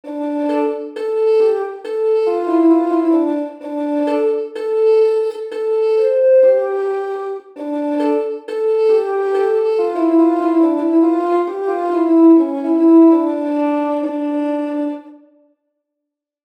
Lira da braccio
Dźwięk wydobywano pocierając struny smyczkiem (jak na skrzypcach). Grywano na niej akordowo.
Dźwięki instrumentów są brzmieniem orientacyjnym, wygenerowanym w programach:
Lira-da-braccio.mp3